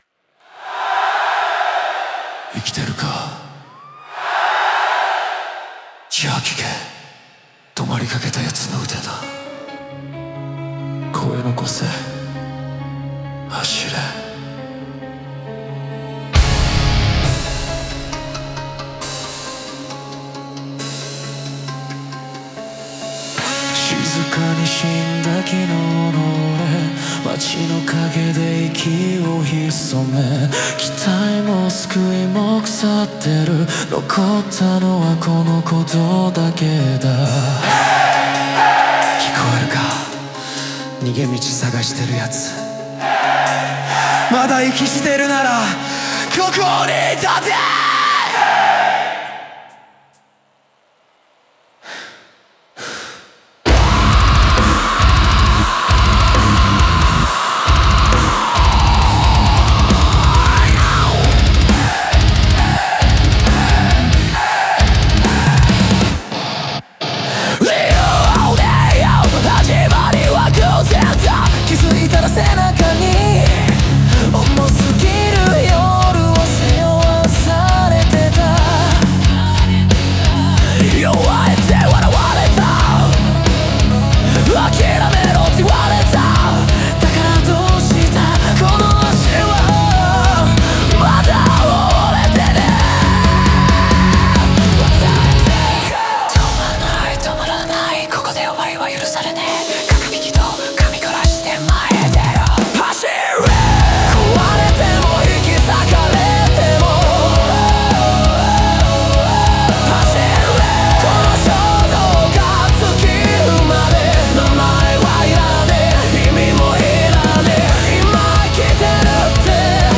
ライブ構成を意識した反復的なコーラスと叫びは、孤独を分断し、集団の熱量として昇華させる役割を担っている。